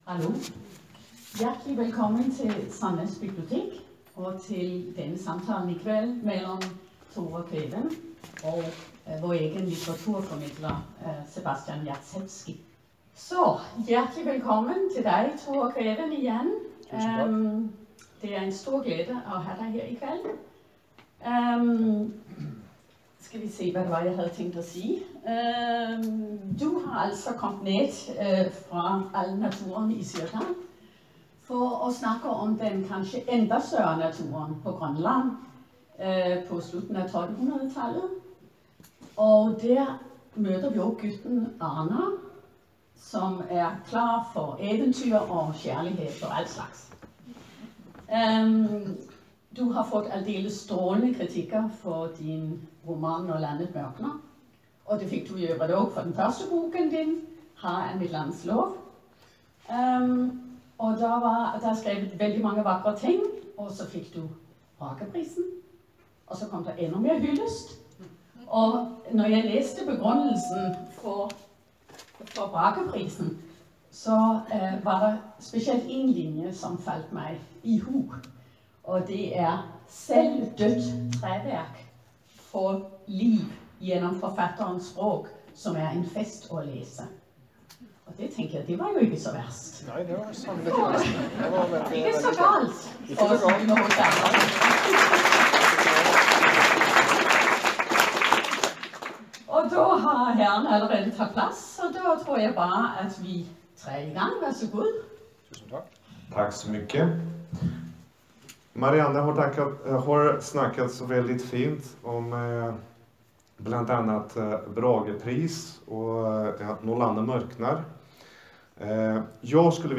En samtale